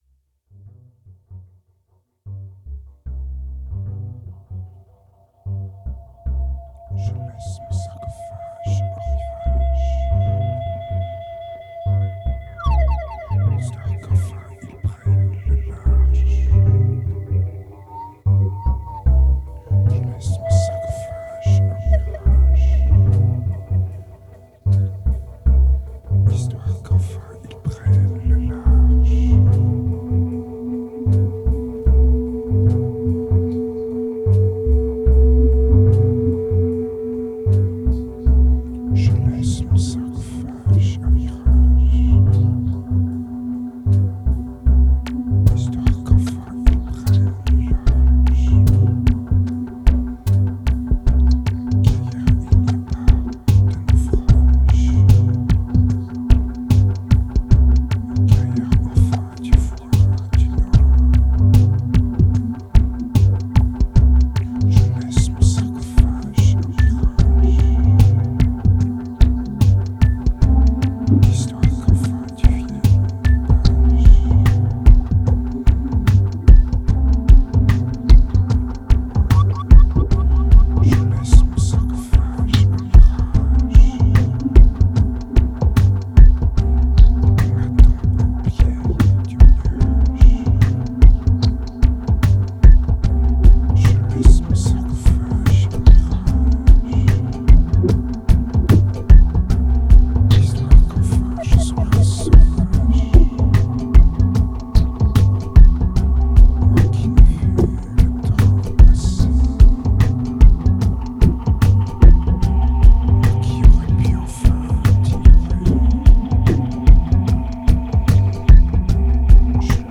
2100📈 - 8%🤔 - 75BPM🔊 - 2010-10-30📅 - -170🌟